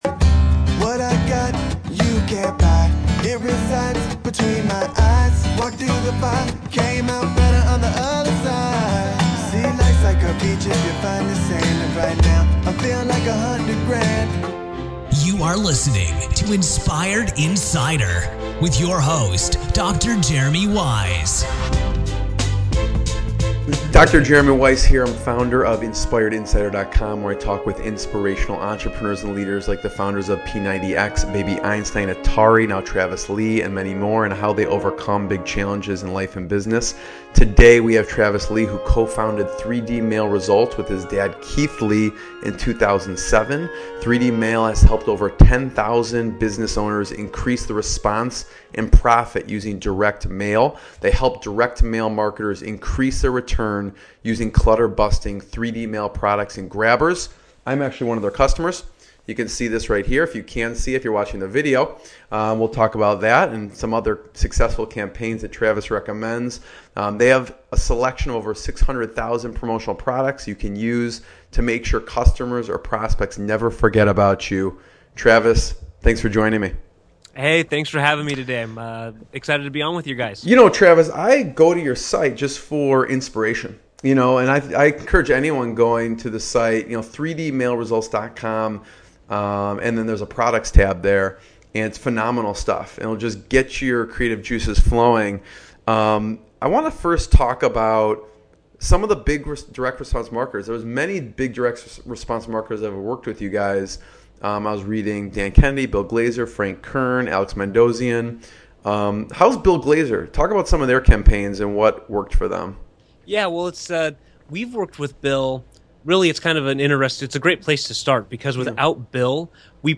INspired INsider - Inspirational Business Interviews with Successful Entrepreneurs and Founders